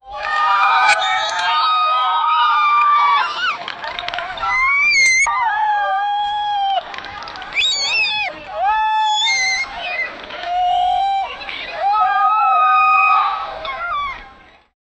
lots-of-monkeys-together--ytptzhbm.wav